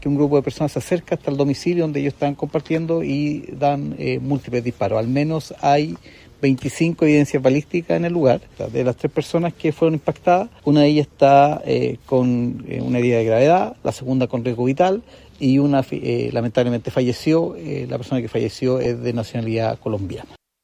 El fiscal ECOH, Rubén Salas, confirmó que el fallecido es de nacionalidad colombiana y que se dispararon al menos 25 tiros.